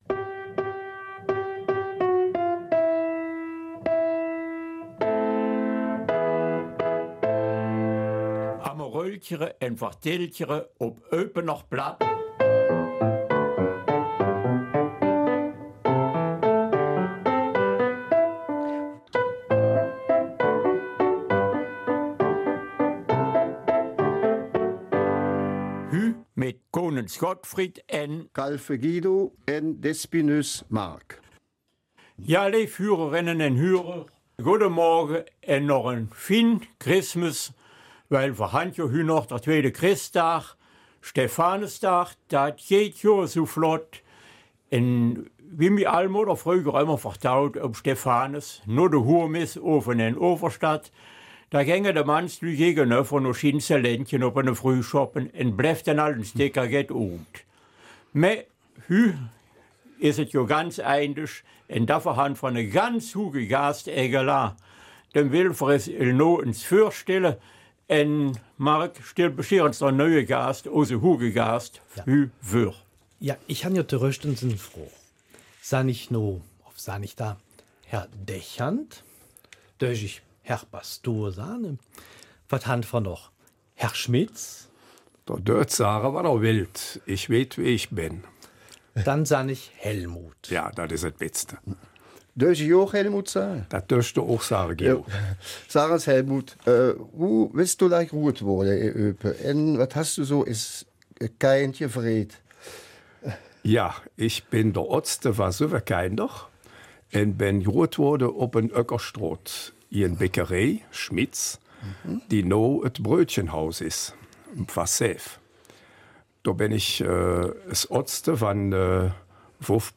Eupener Mundart: Interview